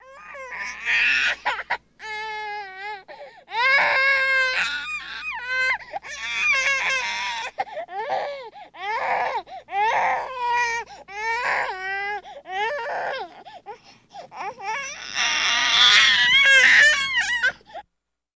Baby Squealing and Complaining